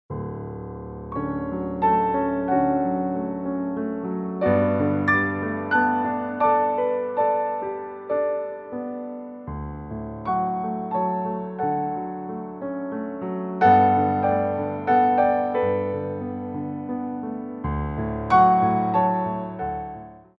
Port de Bras
3/4 (8x8)